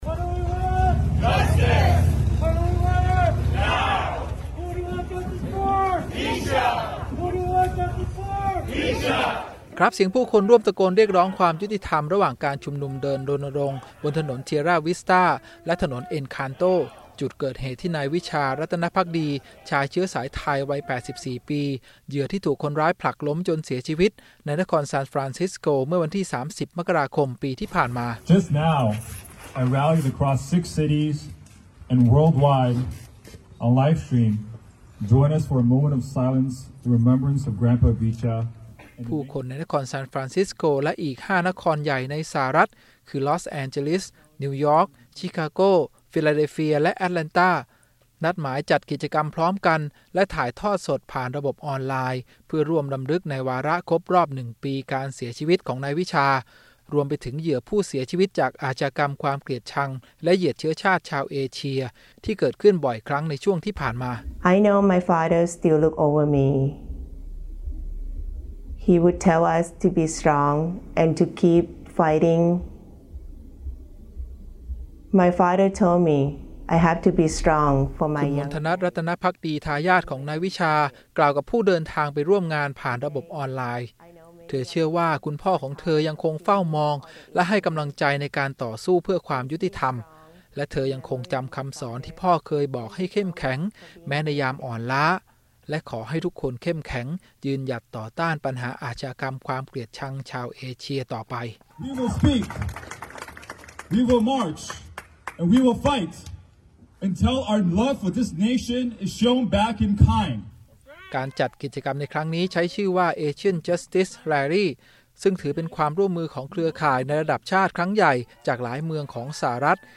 การจัดกิจกรรมครั้งนี้ ใช้ชื่อว่า 'Asian Justice Rally' ซึ่งถือเป็นความร่วมมือของเครือข่ายการรณรงค์ในระดับชาติครั้งใหญ่จากหลายเมืองของสหรัฐฯ ที่จัดขึ้นในวันและเวลาเดียวกันเป็นครั้งแรกเพื่อร่วมต่อต้านอาชญากรรมที่เกิดขึ้นกับชาวเอเชีย โดยมุ่งแสดงจุดยืนสร้างการรับรู้ต่อสังคมอเมริกันในการที่จะไม่นิ่งเฉยกับสิ่งเหล่านี้อีกต่อไป
Protesters hold placards during the Asian Justice Rally.